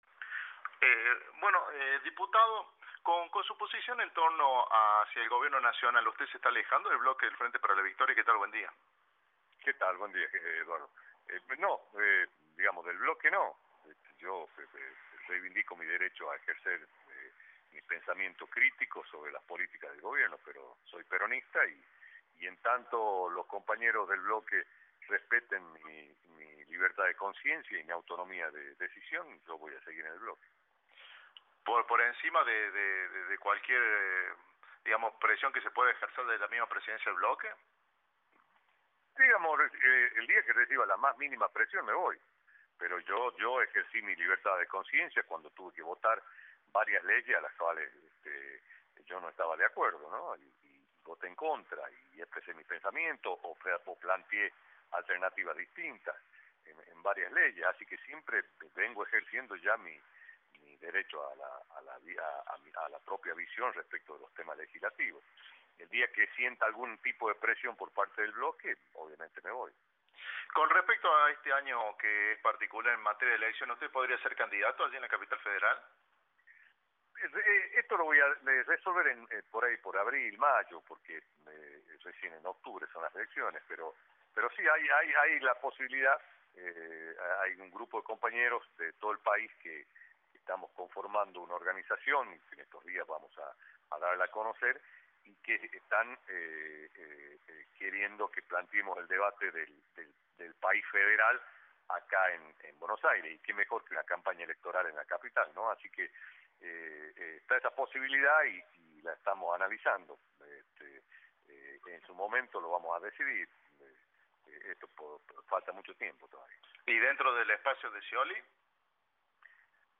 Jorge Yoma, diputado nacional, por Cadena 3